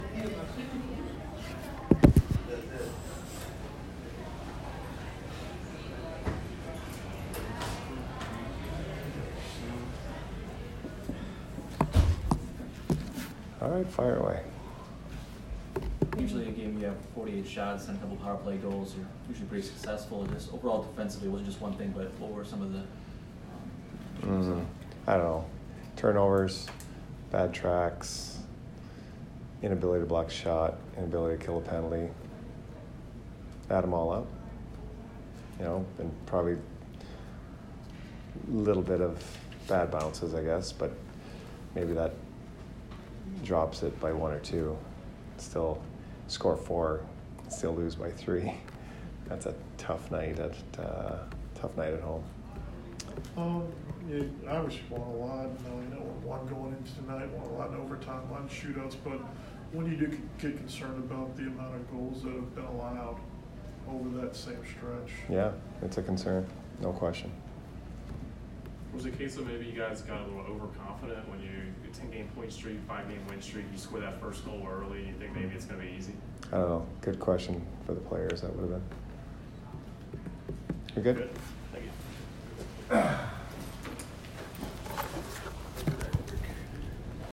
Jon Cooper post-game 3/13